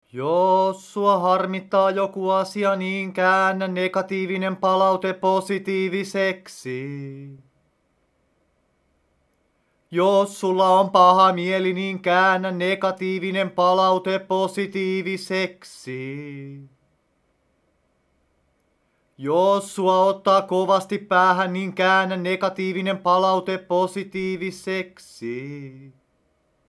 Hän on a cappella -lauluyhtye, jonka jäsenet ovat suurelta osin lahtelaistuneita.